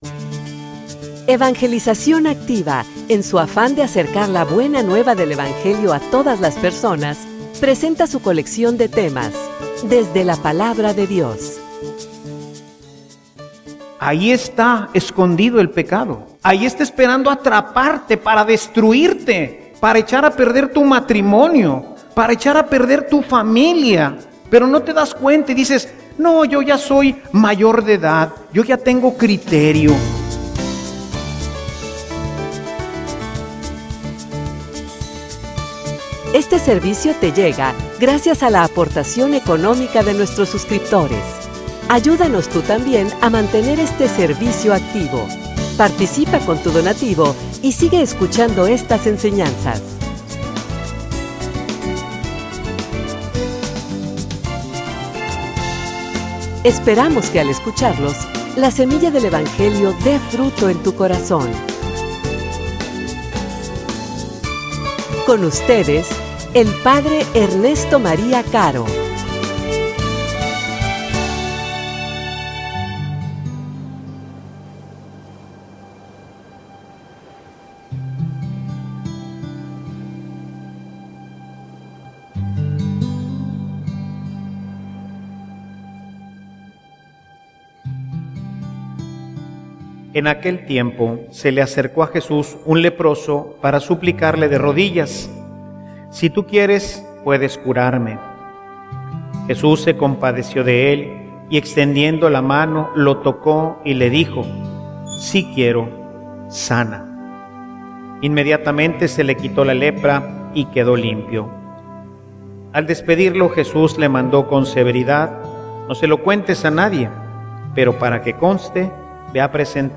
homilia_Un_ministerio_que_sana.mp3